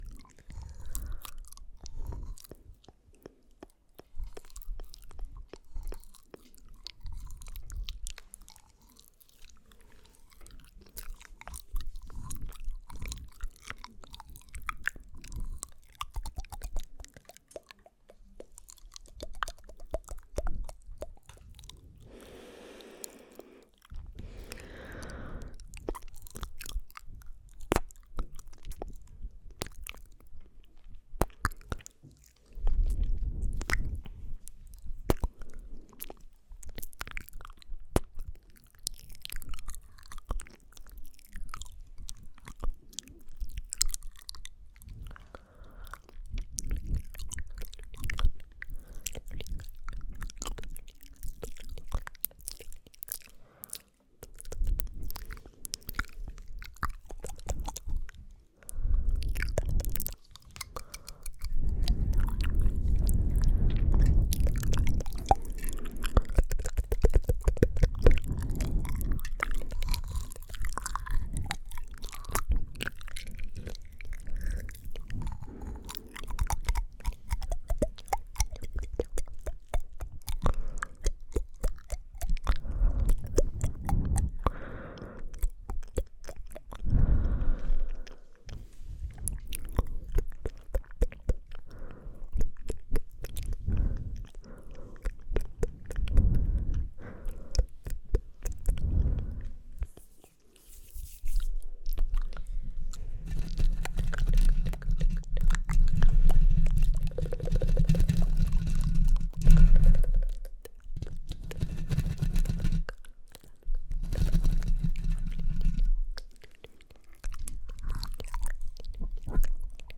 Свист, щелчки языком, цоканье, шепот, мычание и другие необычные звуковые эффекты.
Asmr звуки изо рта